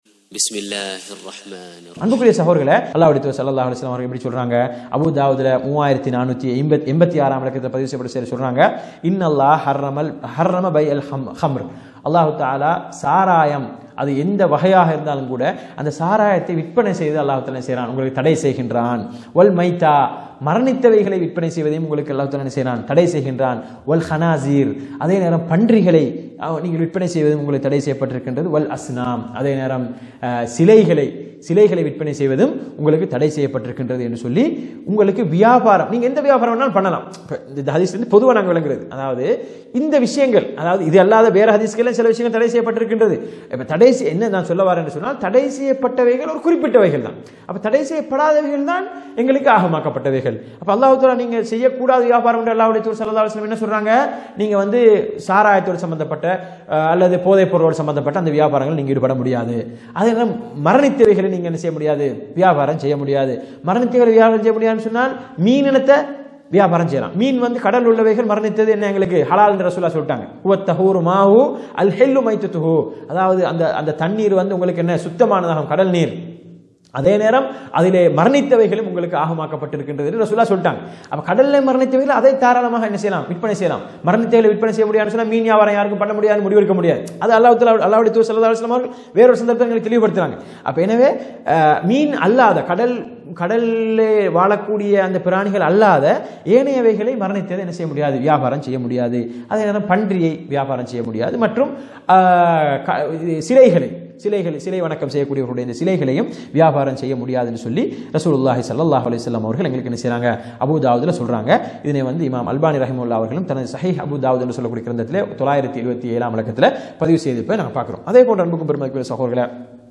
அஷ்ஷைக் முஹம்மது ஸாலிஹ் அல்-முனஜ்ஜித் அவர்களால் எழுதப்பட்ட ‘மக்களின் பார்வையில் சாதாரணமாகிவிட்ட தீமைகள் என்ற நூலின் விளக்க உரையிலிருந்து…